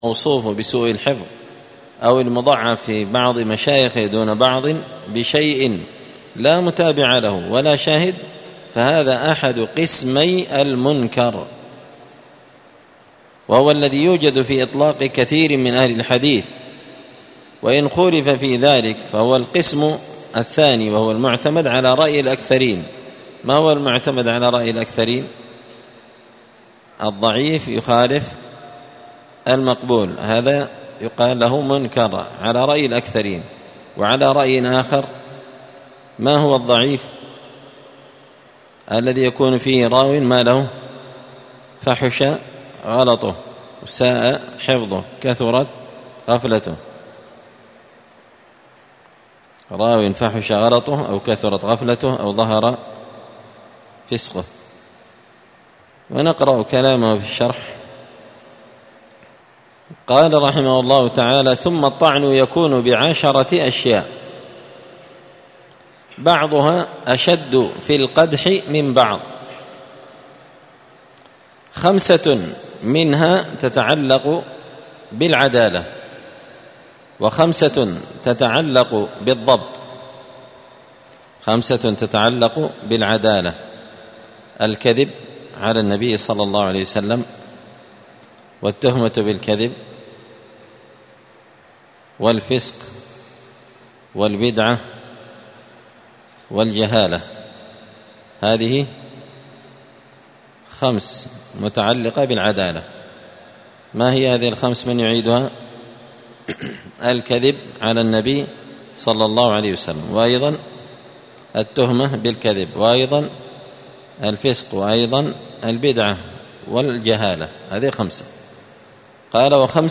تعليق وتدريس الشيخ الفاضل:
الجمعة 16 صفر 1445 هــــ | الدروس، دروس الحديث وعلومه، نزهة النظر بشرح نخبة الفكر للحافظ ابن حجر | شارك بتعليقك | 94 المشاهدات